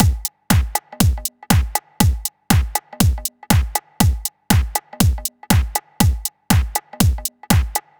Session 14 - Mixed Beat 03.wav